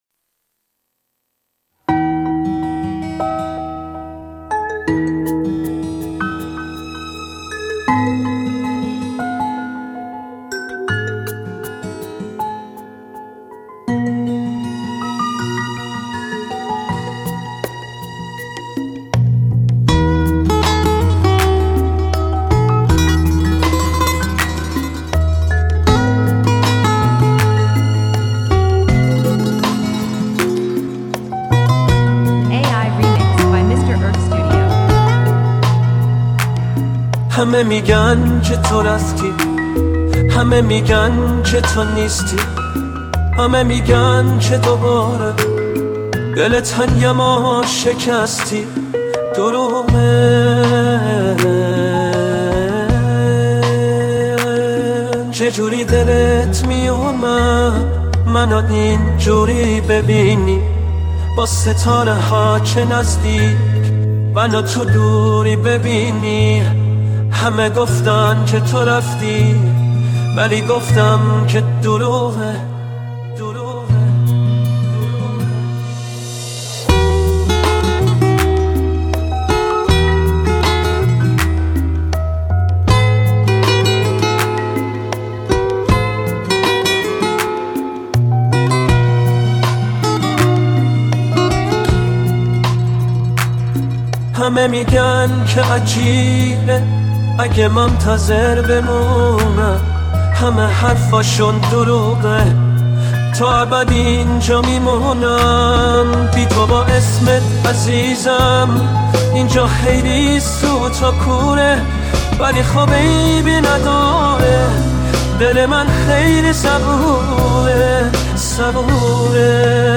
دانلود آهنگ پاپ ایرانی